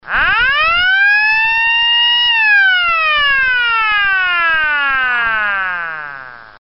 SIRENAS ELECTROMECÁNICAS FIBRA
104dB - 1400Hz